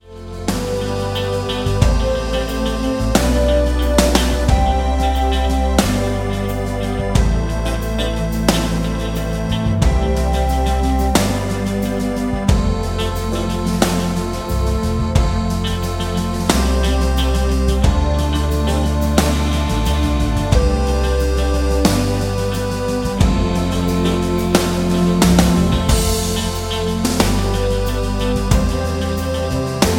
Am
Backing track Karaoke
Rock, 1980s